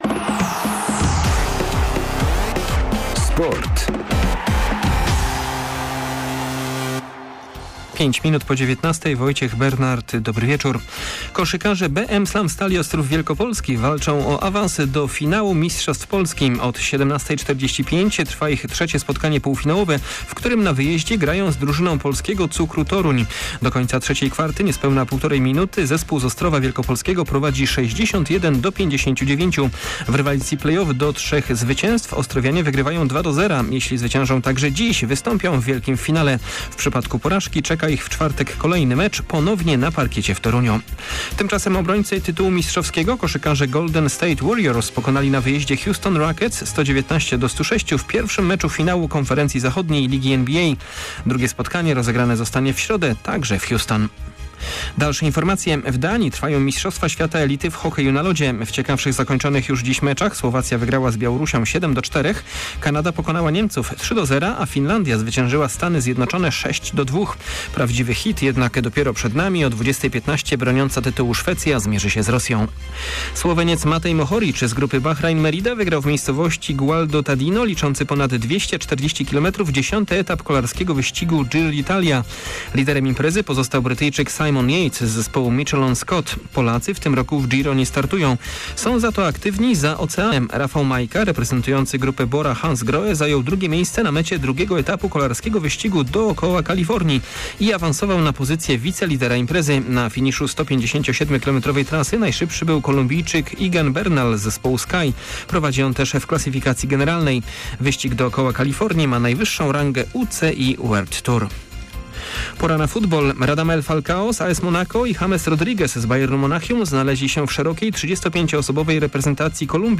15.05 serwis sportowy godz. 19:05